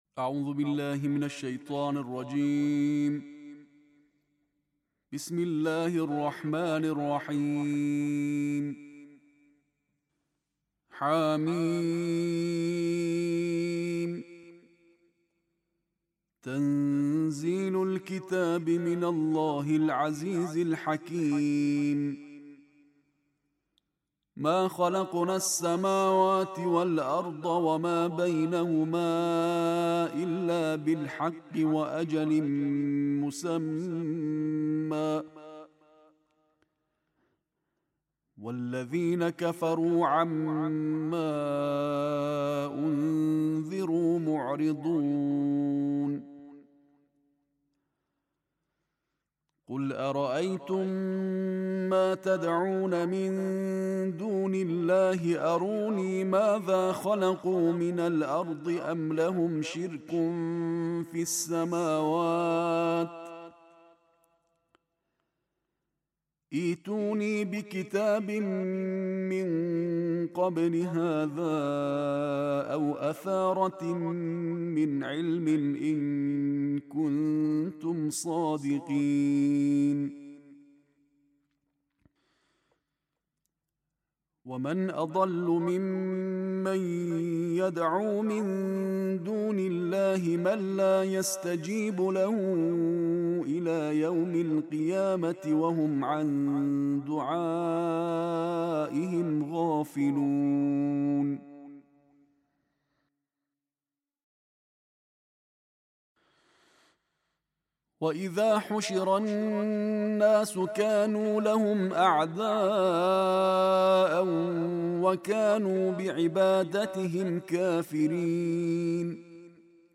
Kila siku na Qur'ani: Qiraa ya Tarteel ya Juzuu ya 26